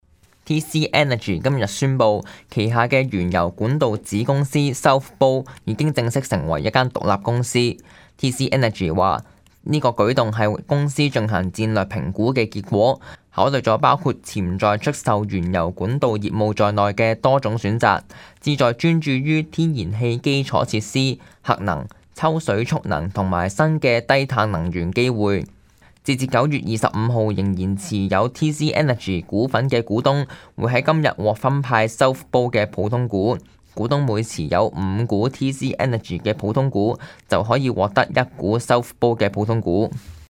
news_clip_20774.mp3